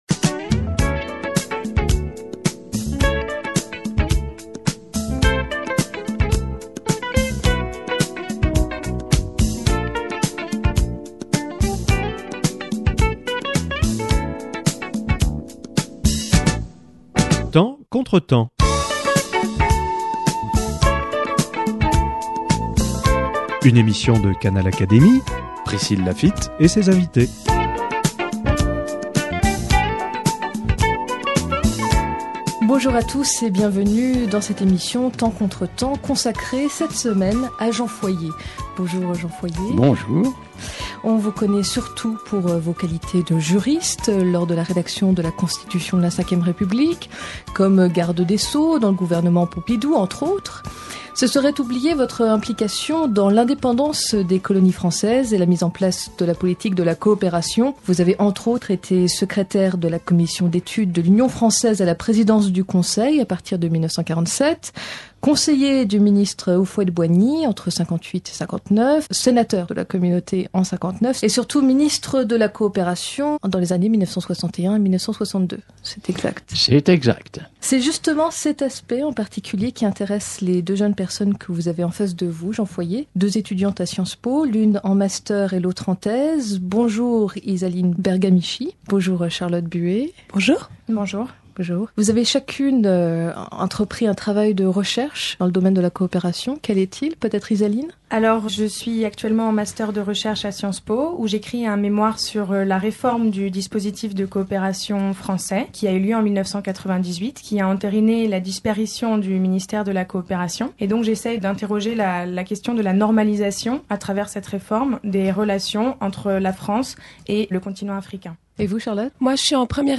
Deux étudiantes en sciences politiques questionnent Jean Foyer sur la politique de coopération et de développement de la France en Afrique.